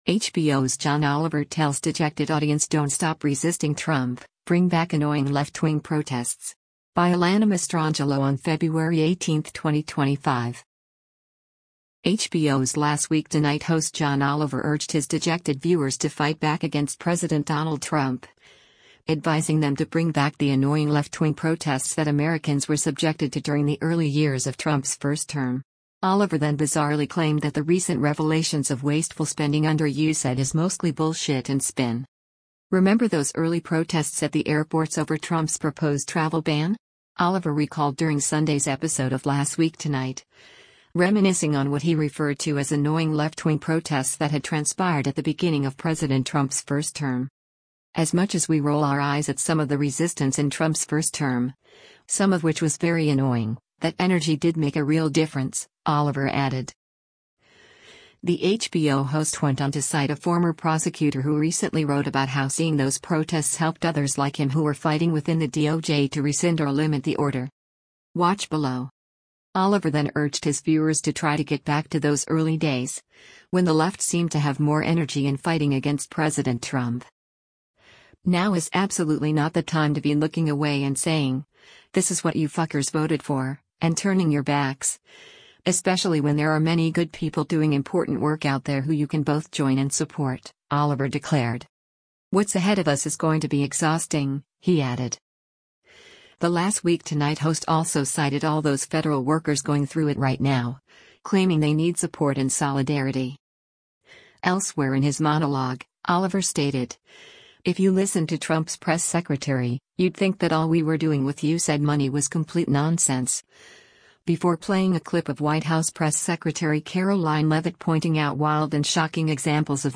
“If that sounds like mostly bullshit and spin to you, it’s because it is,” the HBO host said, eliciting groans from his live audience members, which some might say suggests he missed the mark.
Oliver then brought his soliloquy back to light-hearted, unsubstantial commentary, stating, “But I will say a DEI musical in Ireland, a transgender Opera in Colombia, and a transgender comic book in Peru all sound like her best guesses for what Emilia Pérez is,” receiving laughter from audience members this time.